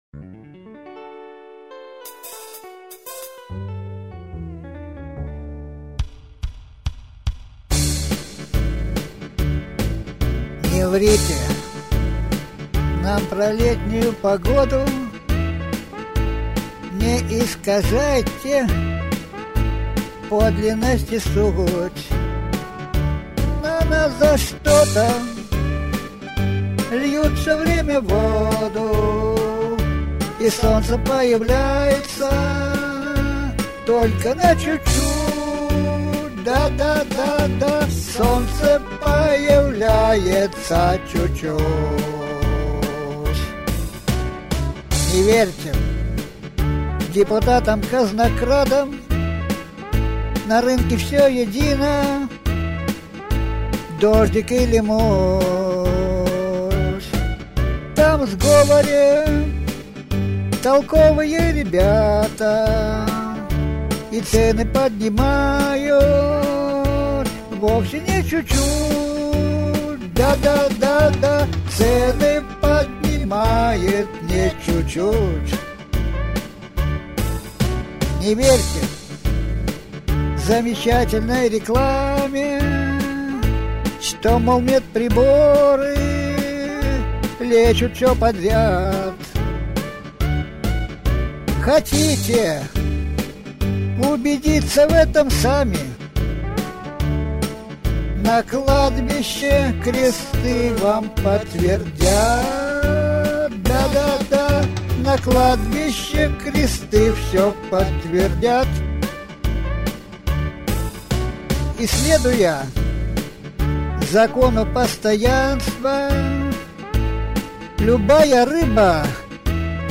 Исполняет автор.